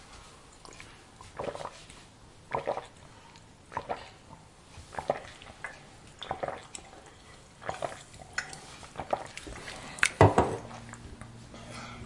搅拌咖啡
描述：搅拌一杯咖啡
标签： 搅拌 饮用水 热水 茶点 咖啡
声道立体声